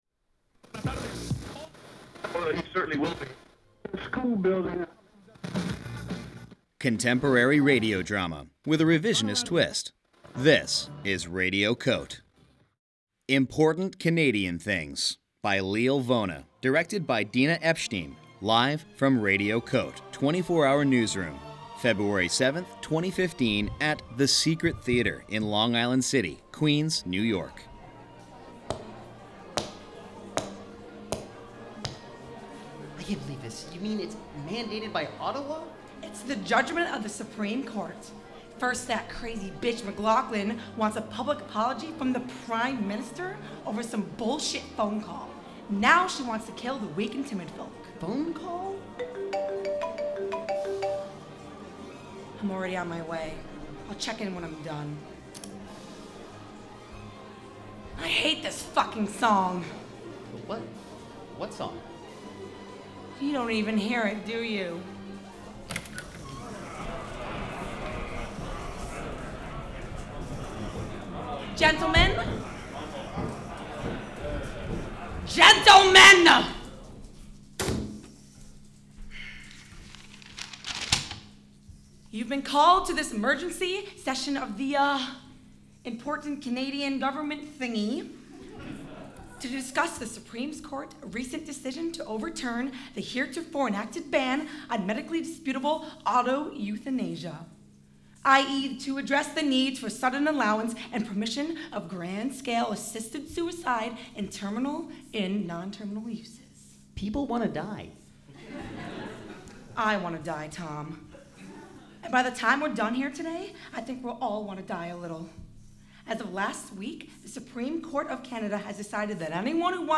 with live foley
Performed and recorded live as part of Radio COTE: 24-hour Newsroom, February 7, 2015 at The Secret Theater, Long Island City, Queens